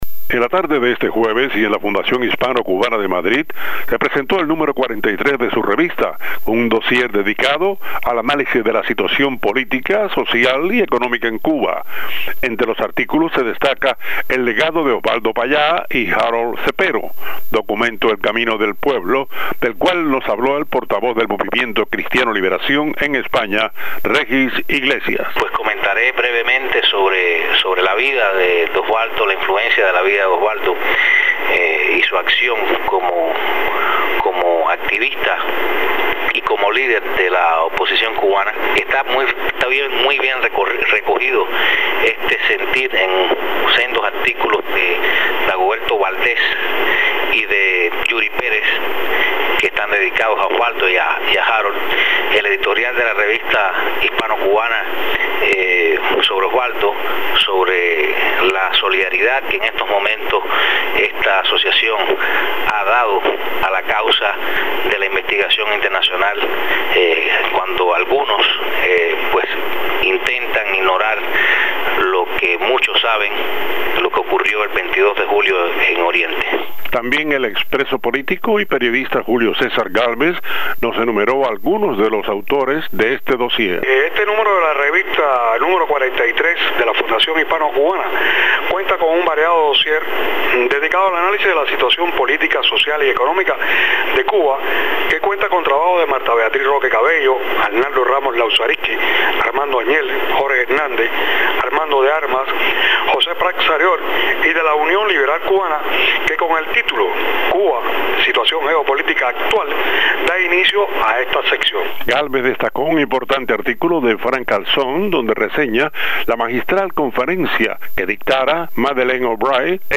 Presentan en Madrid el último número de la revista de la Fundación Hispano Cubana. Dos ex presos políticos cubanos presentes en el acto conversaron con Radio Martí sobre el legado de Oswaldo Payá y Harold Cepero y detallaron alguno de los trabajos de autores cubanos publicados en el número 43 de la revista.